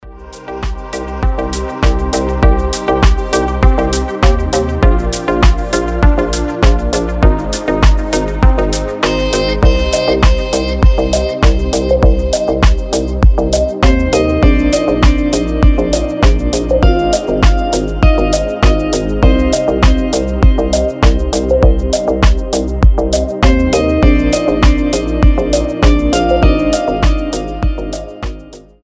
• Качество: 320, Stereo
deep house
атмосферные
красивая мелодия
релакс
Прекрасный deep house